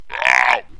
Epic Noise - AOH AWESOME
Category: Sound FX   Right: Personal